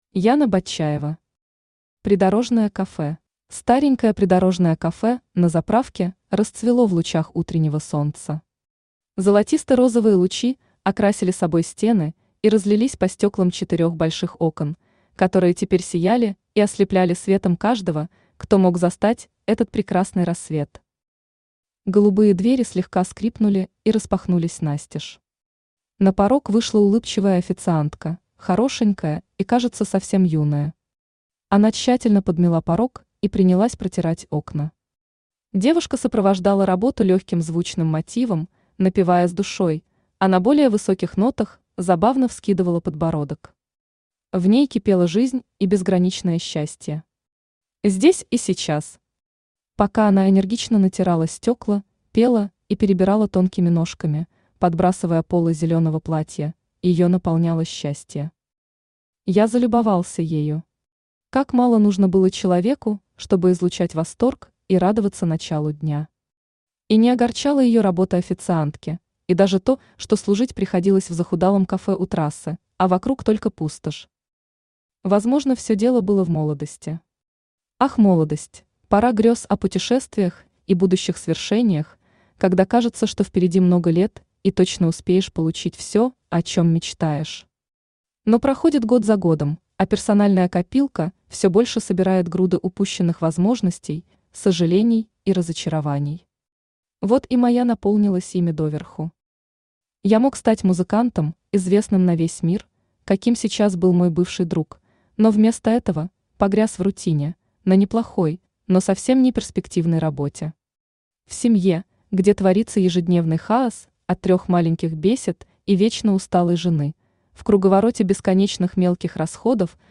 Аудиокнига Придорожное кафе | Библиотека аудиокниг
Aудиокнига Придорожное кафе Автор Яна Батчаева Читает аудиокнигу Авточтец ЛитРес.